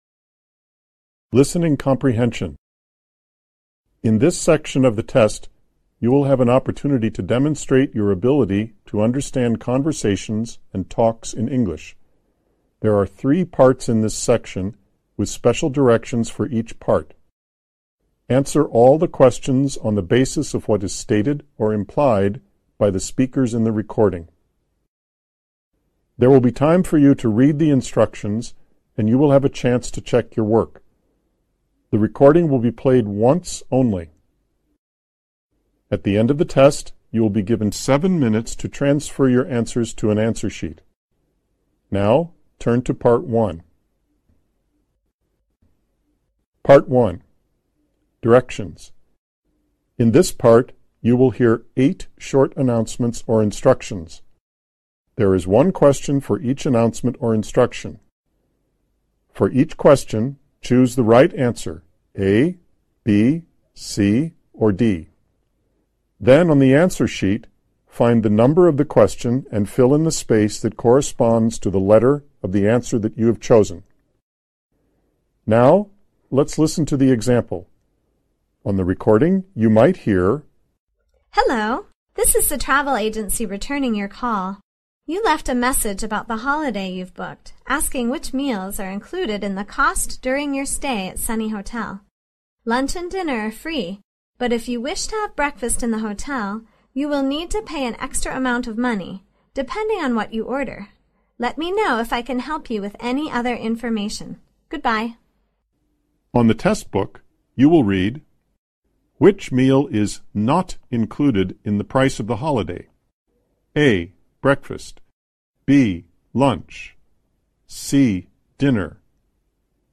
Directions: In this part, you will hear EIGHT short announcements or instructions.
Woman: Hello.